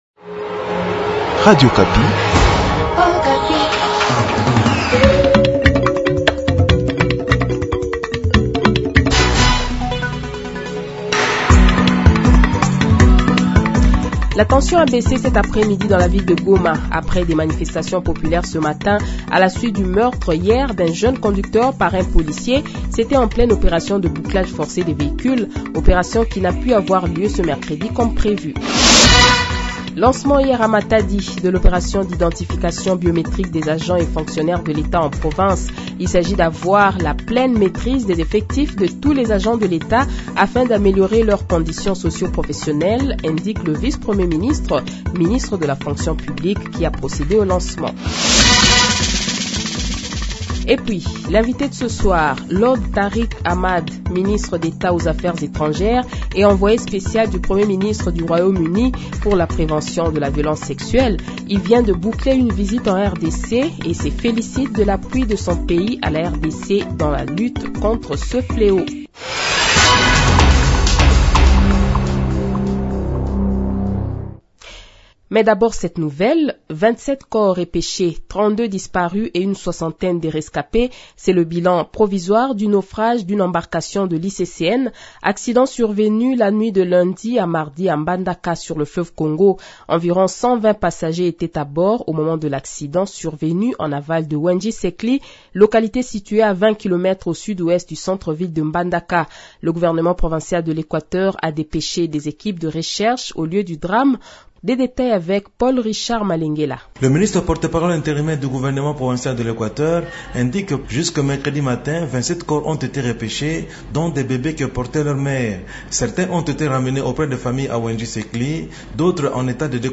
Le Journal de 18h, 12 Octobre 2022 :